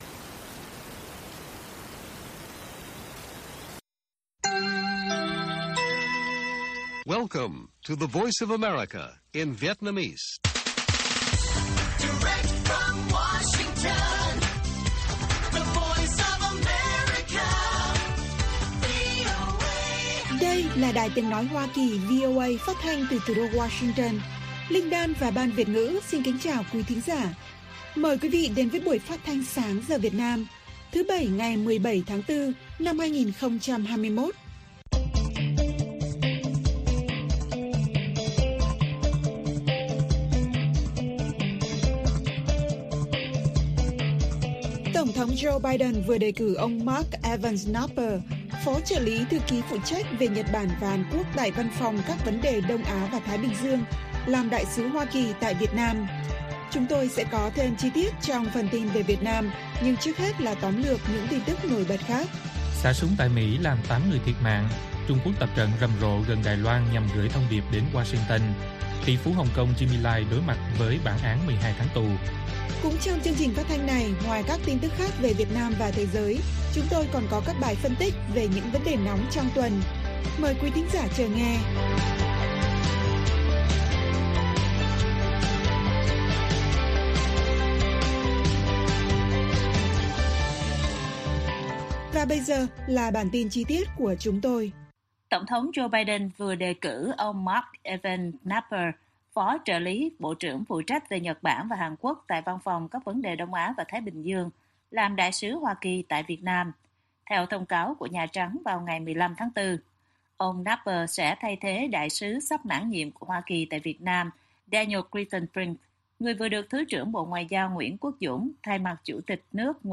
Bản tin VOA ngày 17/4/2021